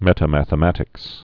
(mĕtə-măthə-mătĭks)